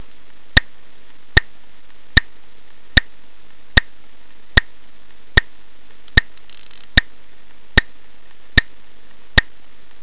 リズムは四拍子。一拍の間に二音節発音します。
一分間に四分音符七十拍の速度 で全篇を発音できれば七十点、
tempo70.wav